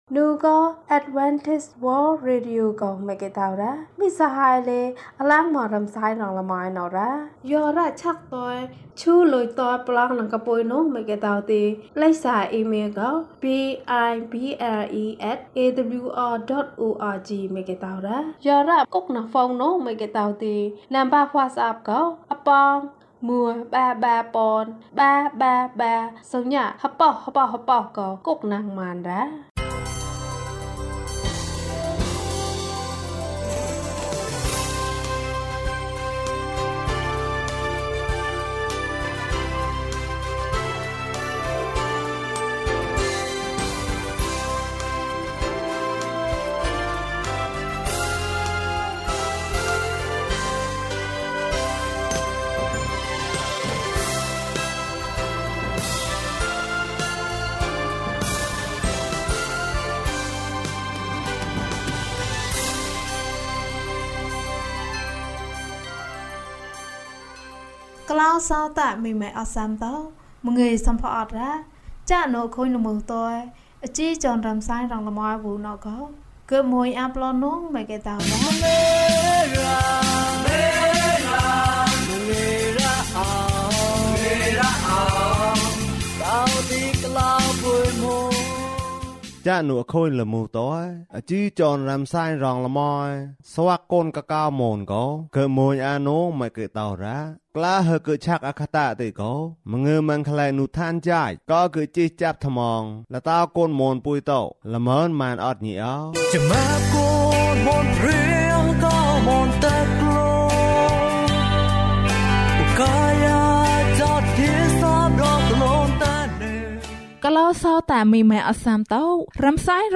ယေရှုက သင့်ကို ခေါ်တယ်။၀၂ ကျန်းမာခြင်းအကြောင်းအရာ။ ဓမ္မသီချင်း။ တရားဒေသနာ။